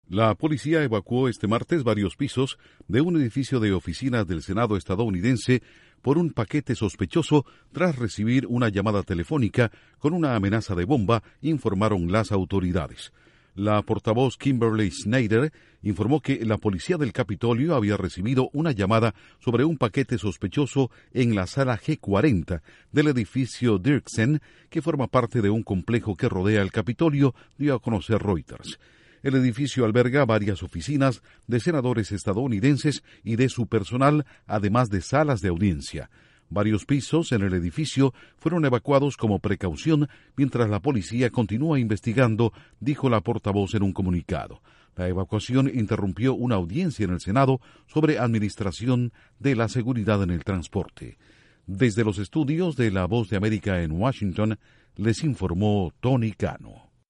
Una amenaza de bomba en oficinas del Senado de Estados Unidos obliga a la evacuación de varios pisos. Informa desde los estudios de la Voz de América en Washington